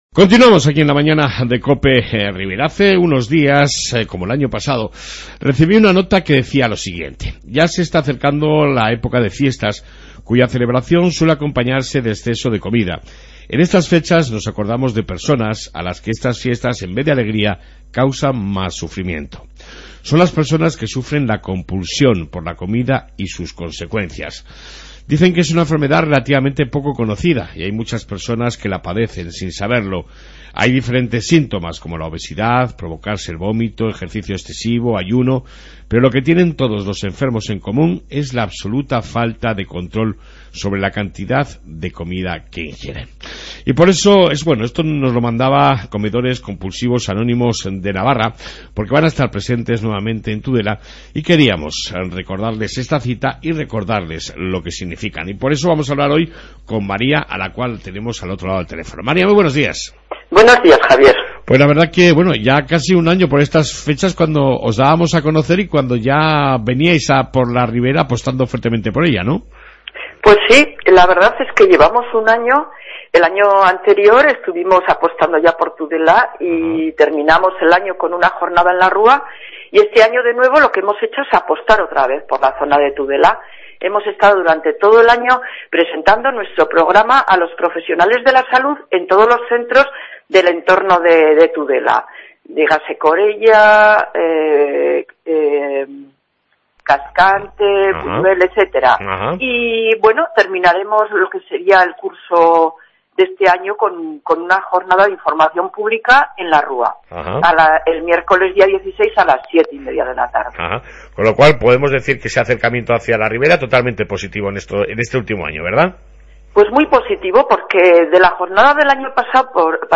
AUDIO: Entrevista con comedores compulsivos de Navarra (Que actualmente cuenta con un grupo en Tudela) y que dan una charla este miercoles a las...